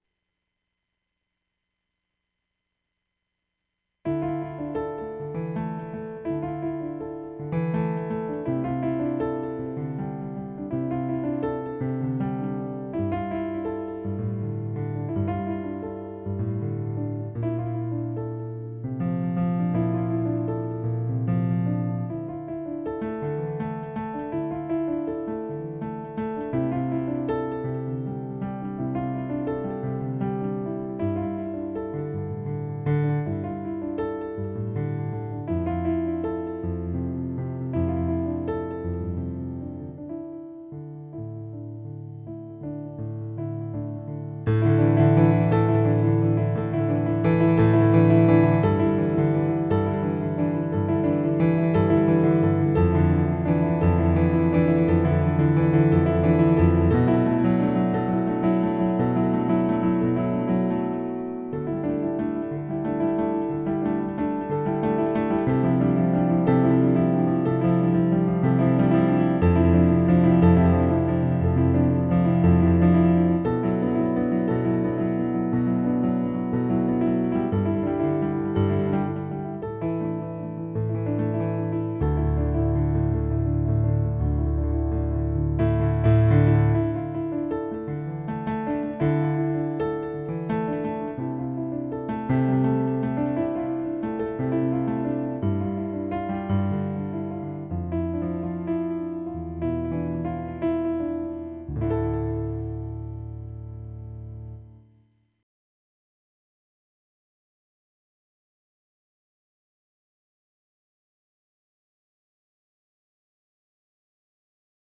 Following my formula of "repeat the melody line over and over with different chords"